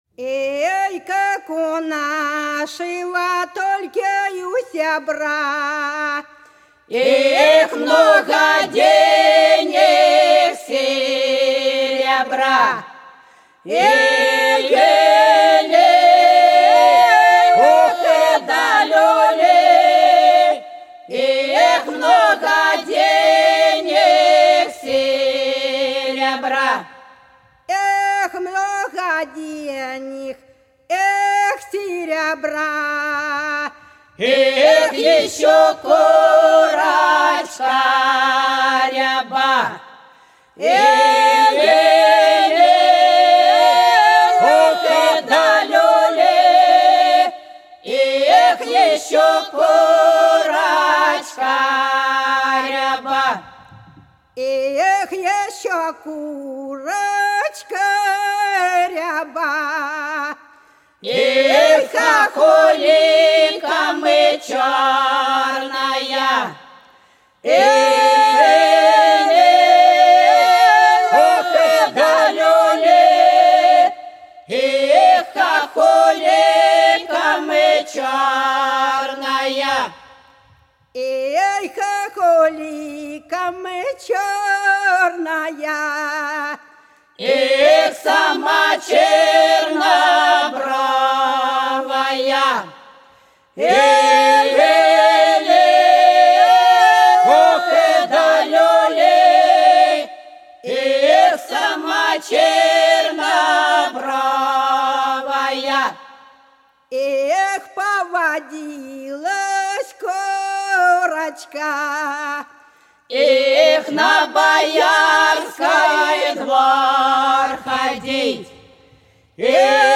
За речкою диво Как у нашего сября - хороводная (с. Гвазда)
09_Как_у_нашего_сября_-_хороводная.mp3